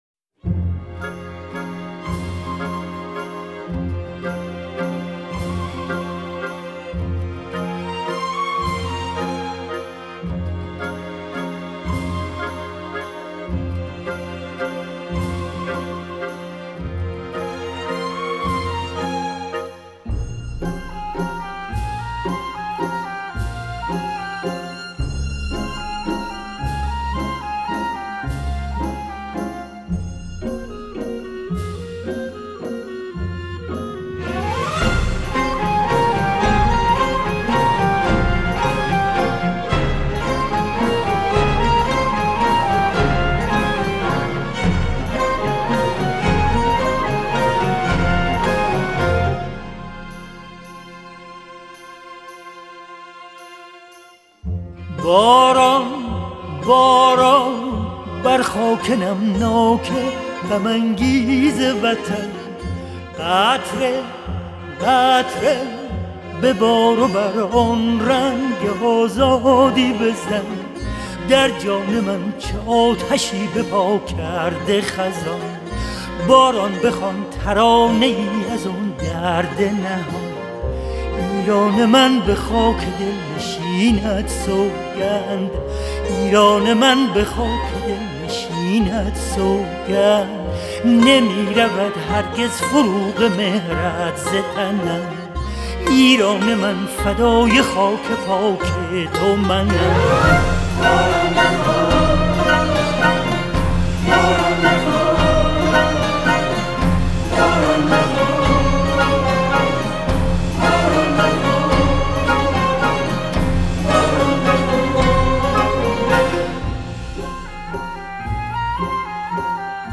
اركستر و آواز